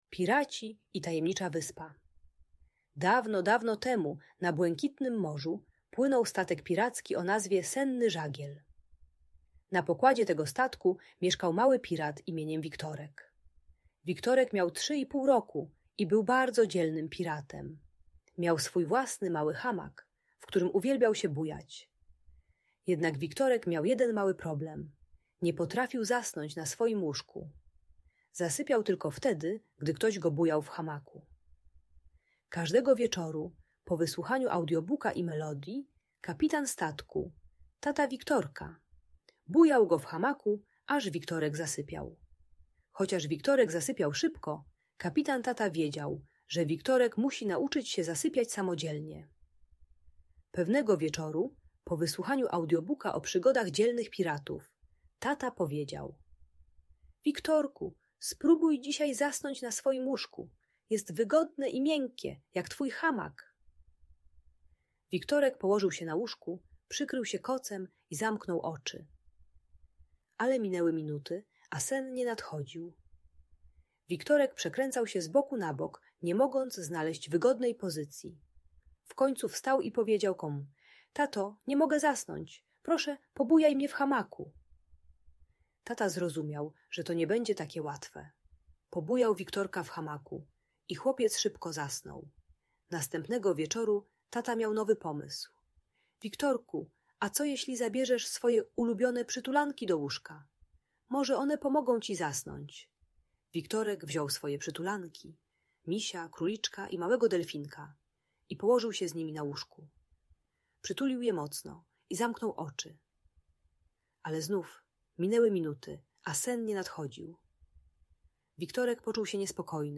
Bajka pomagająca zasnąć dla 3-4 latka, które nie chce zasypiać samo i potrzebuje bujania lub obecności rodzica. Ta audiobajka usypiająca dla przedszkolaka uczy techniki wizualizacji – wyobrażania sobie spokojnego miejsca przed snem.